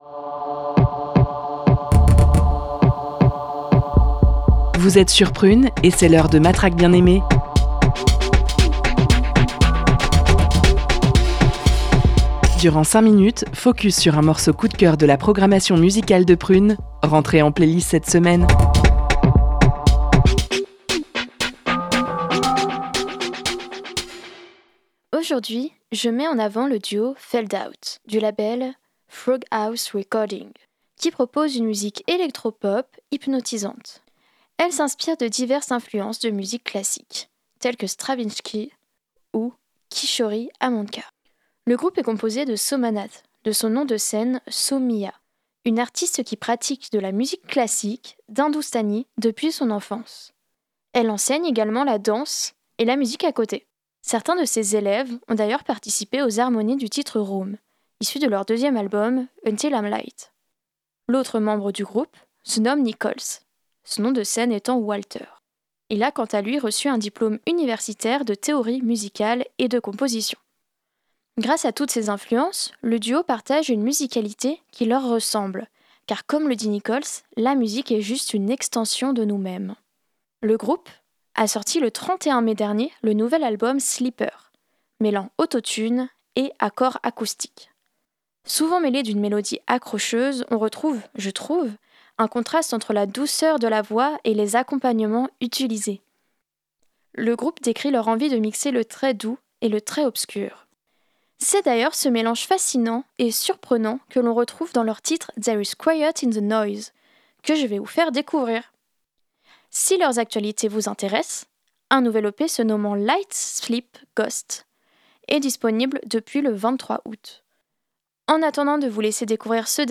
duo electro pop